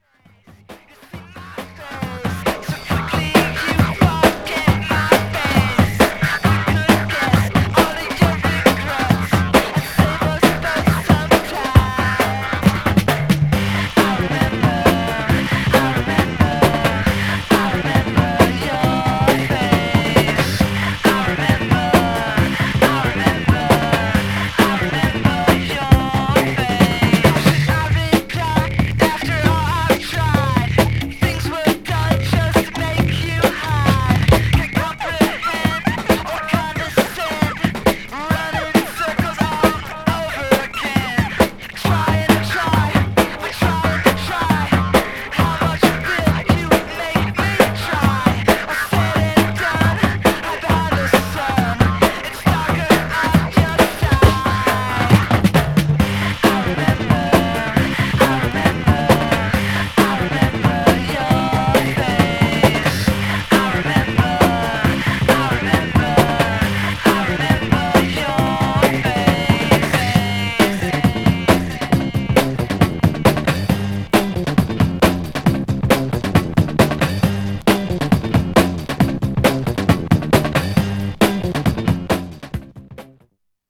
Styl: Techno, Breaks/Breakbeat